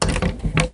door-open-3.ogg